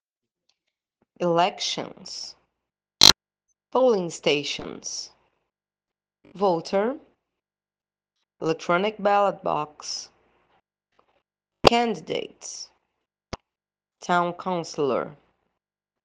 Repeat after me: